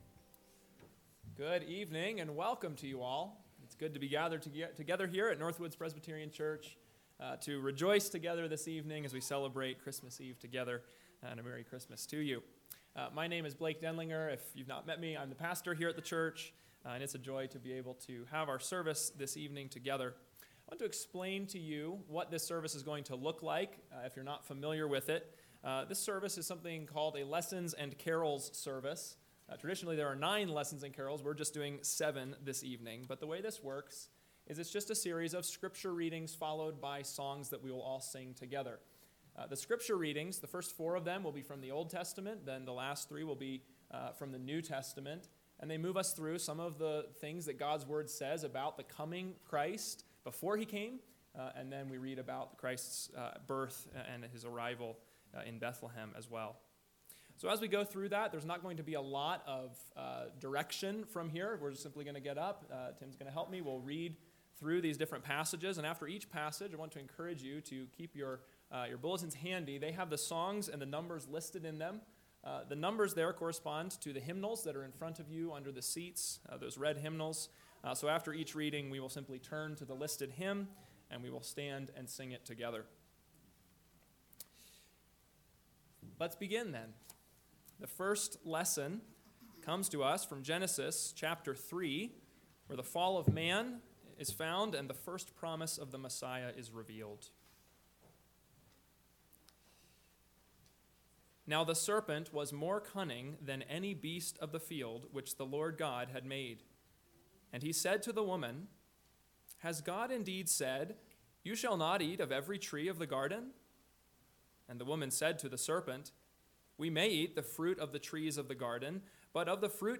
Christmas Eve Service, December 24th, 2024
Lessons and Carols